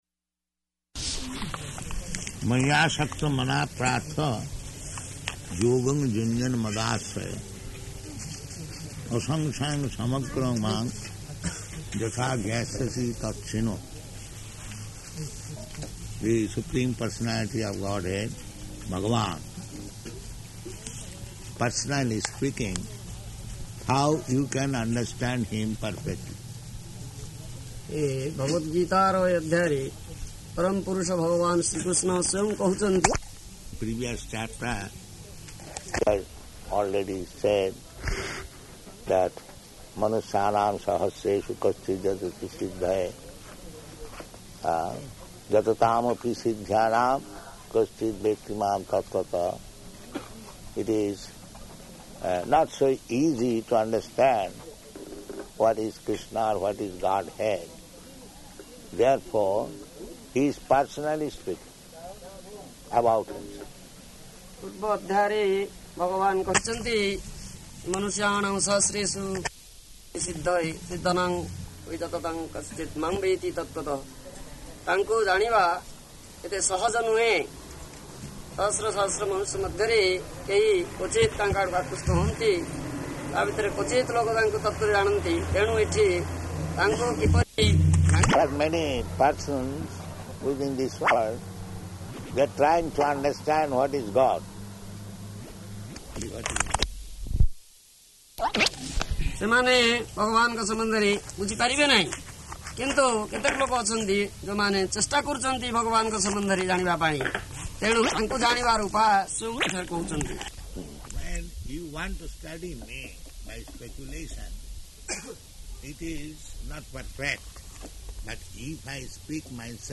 Location: Bhubaneswar